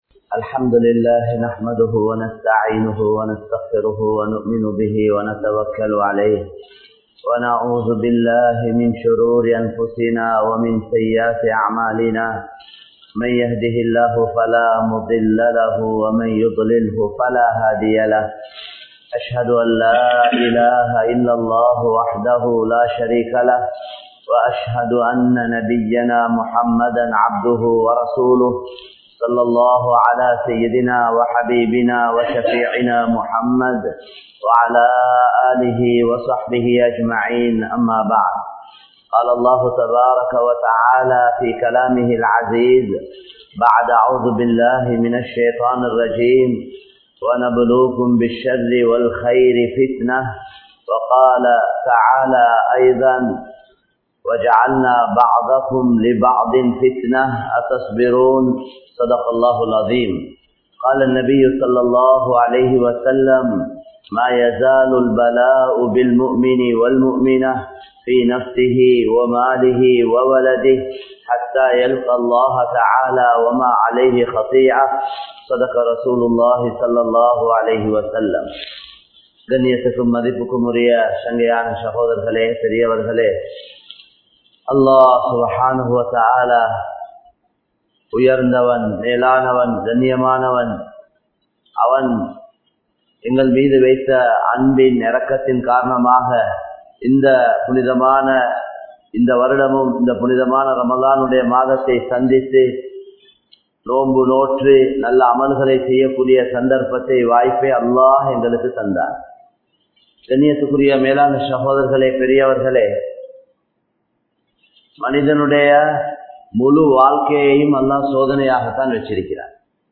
Nallavarhal Soathikka Paduvaarhal (நல்லவர்கள் சோதிக்கபடுவார்கள்) | Audio Bayans | All Ceylon Muslim Youth Community | Addalaichenai
Wellampitiya, Polwatte, Masjidun Noor Jumua Masjidh